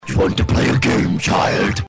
doyouwanttoplayagamechild.mp3